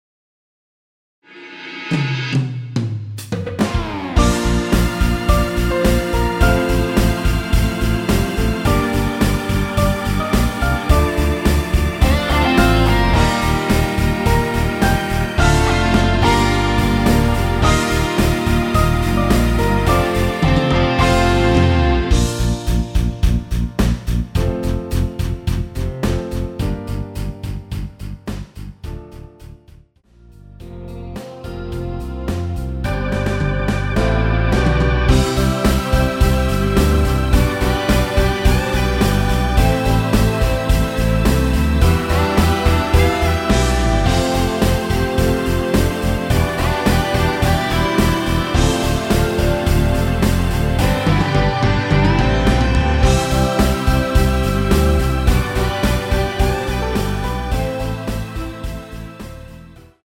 원키에서(-2)내린 MR입니다.
Bb
반주가 더없이 탄탄하고 분위기 또한 리얼한 편에 속하네요
앞부분30초, 뒷부분30초씩 편집해서 올려 드리고 있습니다.
중간에 음이 끈어지고 다시 나오는 이유는